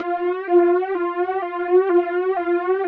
alien_alarm.wav